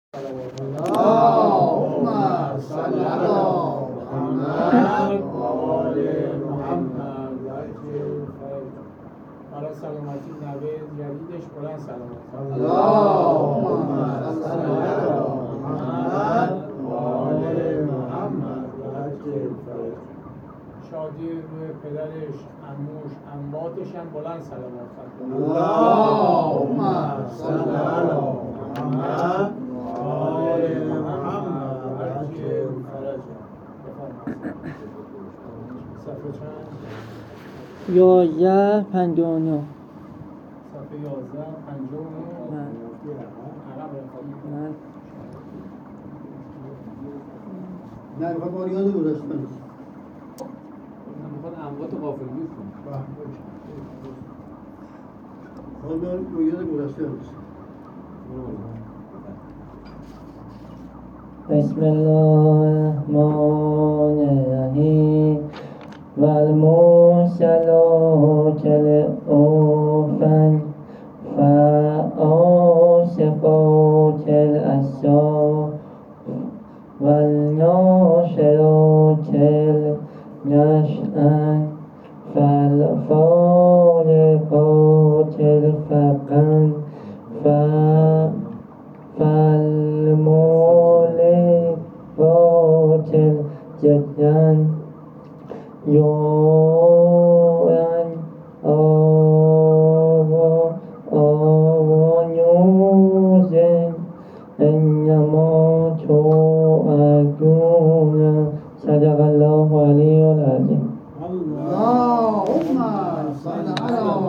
قرائت قرآن کریم